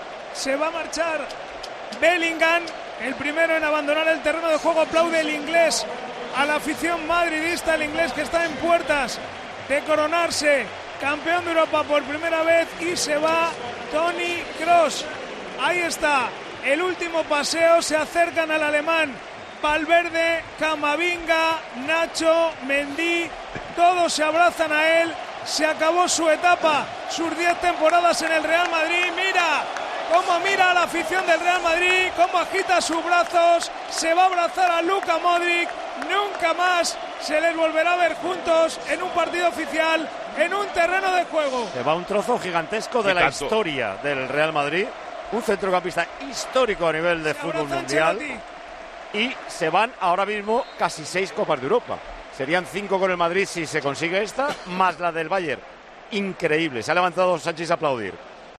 En el siguiente audio podrás escuchar cómo vivimos este momento histórico en Tiempo de Juego y las palabras de Paco González dedicadas al '8' merengue: "Se va un trozo gigantesco de la historia del Real Madrid, histórico a nivel mundial".